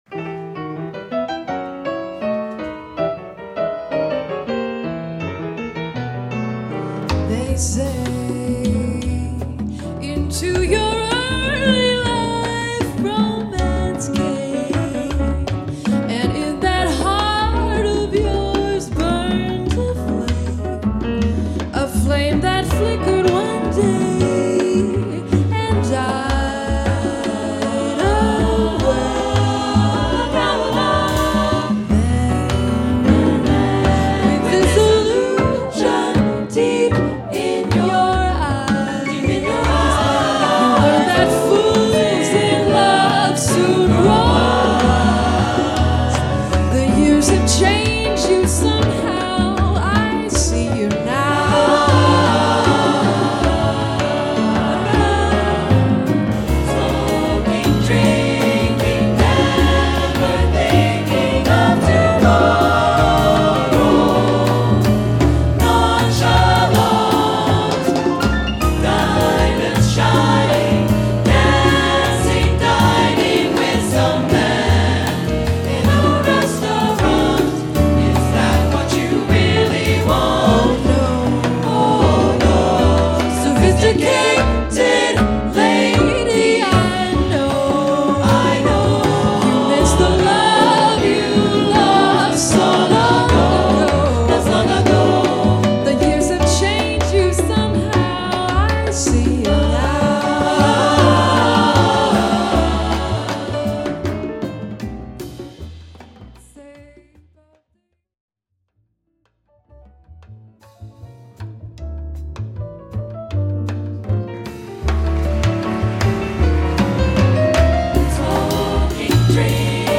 SATB/rhythm